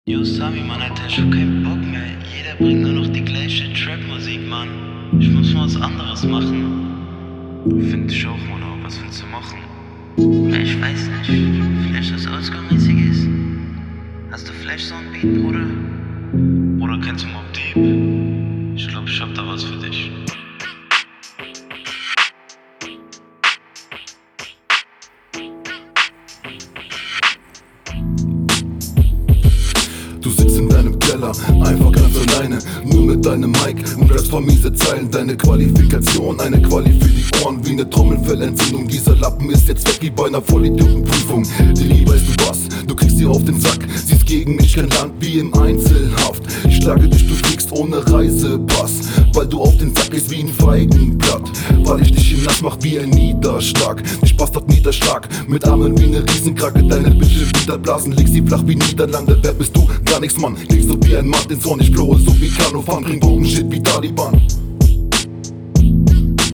finde dich stimme kommt gut, manchmal sind die endwörter etwas unverständlich aber insgesamt klingt das …
Soundqualität: Man hat es größtenteils verstanden. Also zum hörgenuss ich würde eas mir privat nicht …
Ist teilweise etwas unverständlich, aber schon cool geflowt für ne Trainrunde. Intro gette ich nicht …